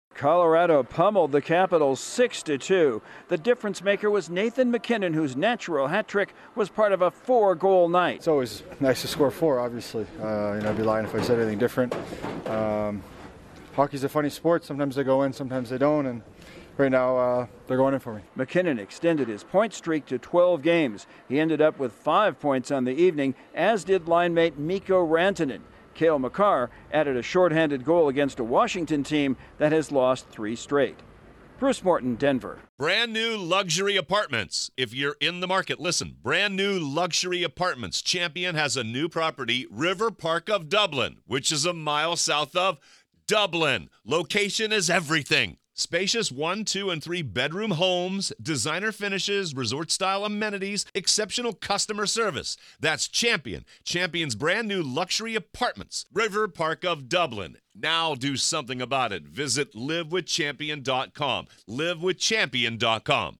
An Avalanche sniper has a five-point night against the Capitals. Correspondent